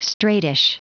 Prononciation du mot straightish en anglais (fichier audio)
Prononciation du mot : straightish
straightish.wav